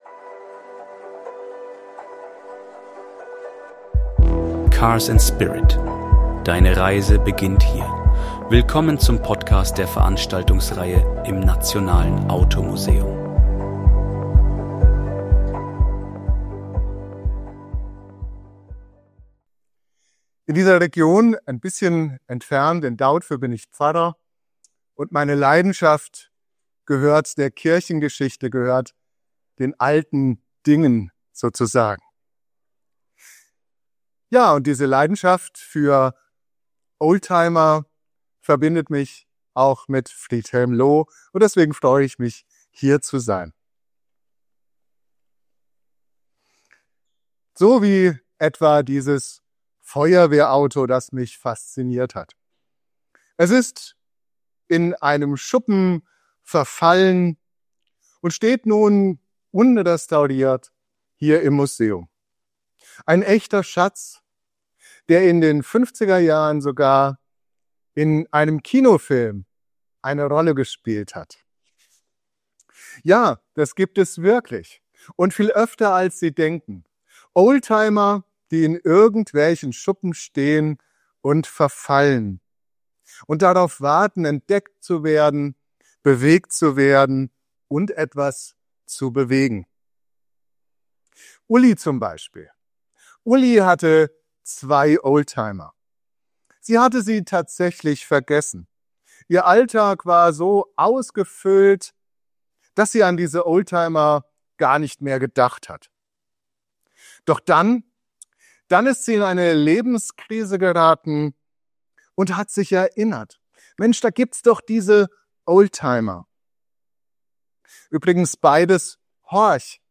Die Veranstaltungsreihe im Nationalen Automuseum.